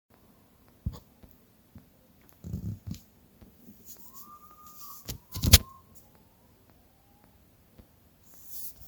Urutaú Común (Nyctibius griseus)
Oído nuevamente fuera de temporada
Condición: Silvestre
Certeza: Vocalización Grabada